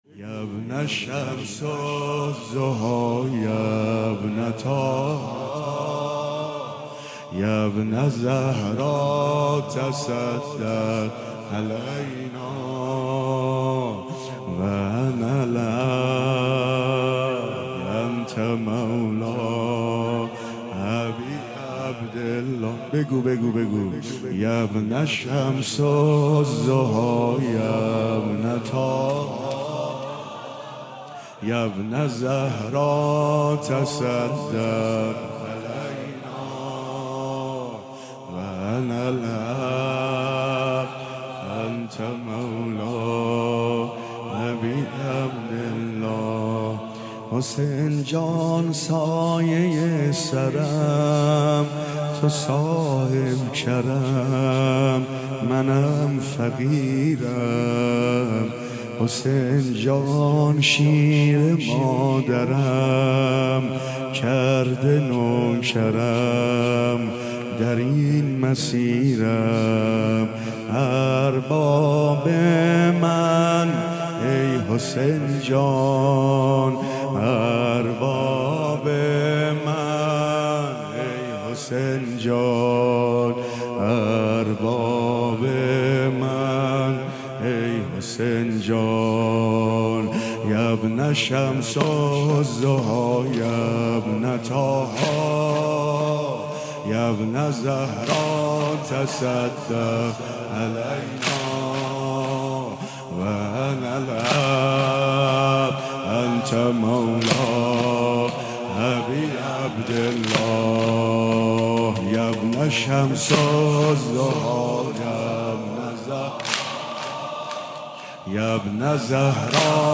مداحی های شب اول محرم هیت الرضا(ع)
زمینه/دوباره روی لبم نشست